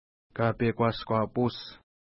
Pronunciation: ka:pejkwa:skwa:pu:s
Pronunciation